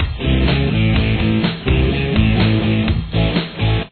Here’s what the intro sounds like with guitar and bass: